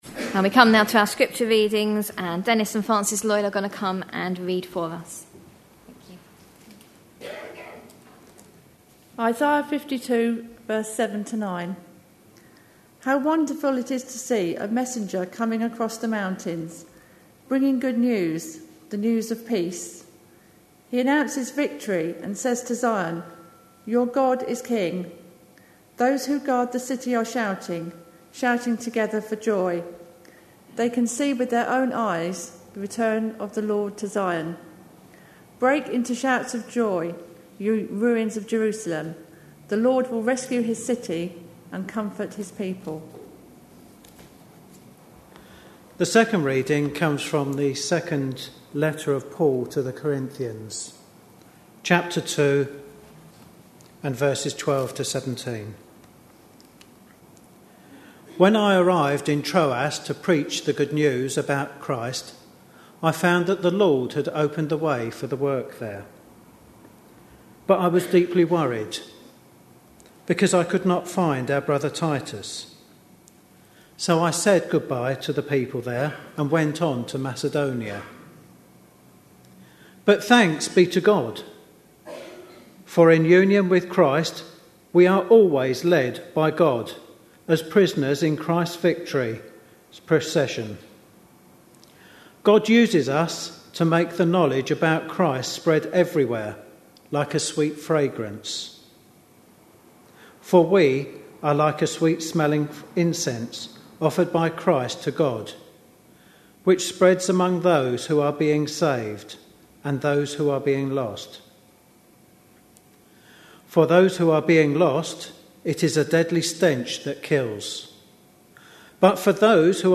A sermon preached on 5th June, 2011, as part of our God At Work In Our Lives. series.